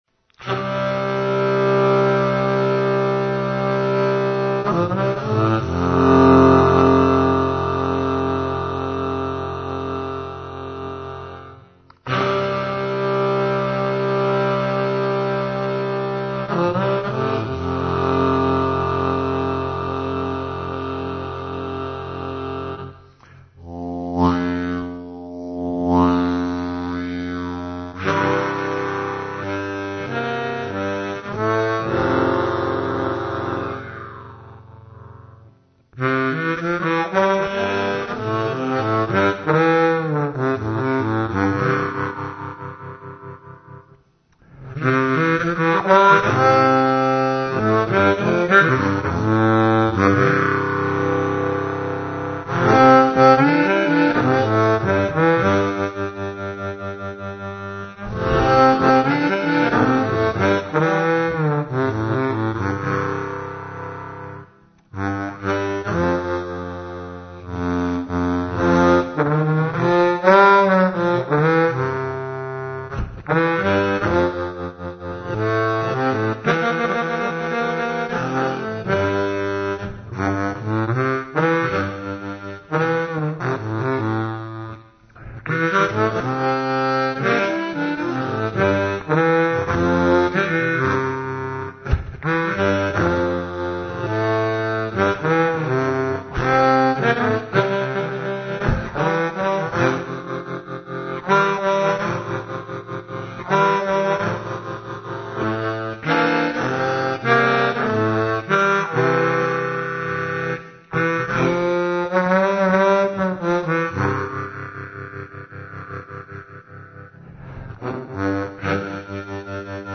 Правда, эти гармоники уже идут как Custom Harps (ручные, не серийные модели) и, следовательно, стоят порядка 50 Евро (~ 60$). Послушайте, как звучит Seydel Blues Session Low Low F гармоника.
superlow.mp3